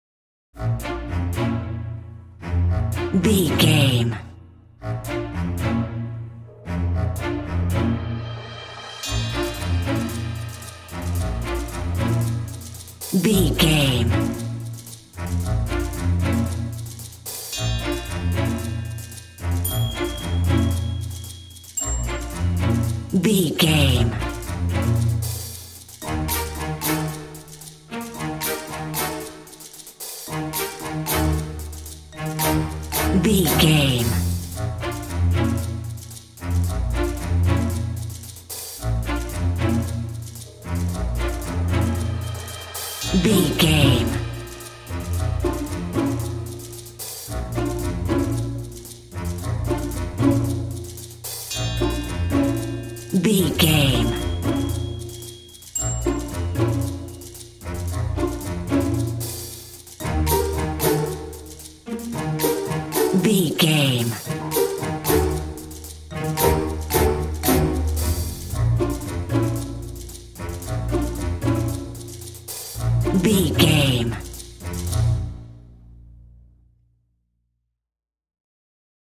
Uplifting
Lydian
B♭
flute
oboe
strings
orchestra
cello
double bass
percussion
silly
circus
goofy
comical
cheerful
perky
Light hearted
quirky